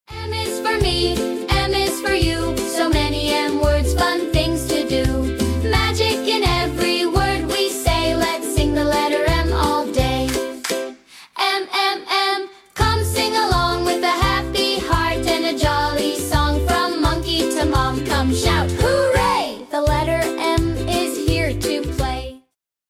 cheerful ABC song